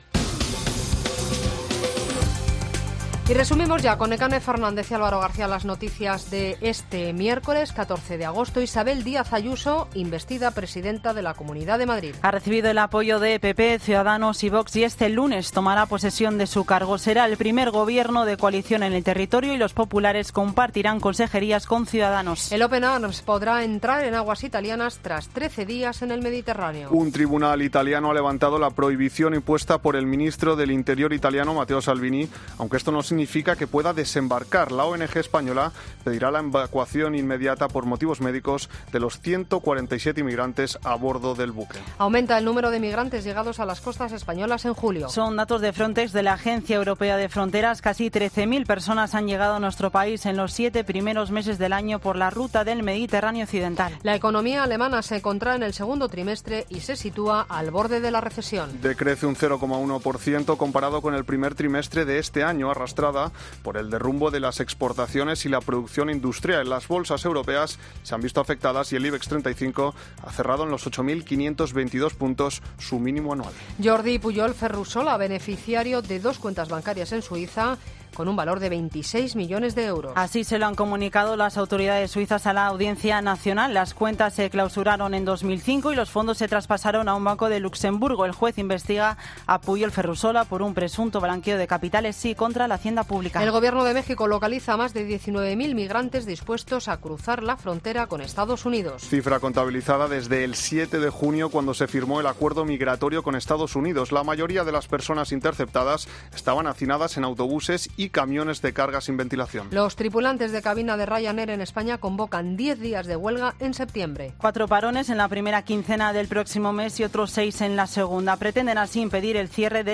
Boletín de noticias Cope del 14 de agosto a las 20.00 horas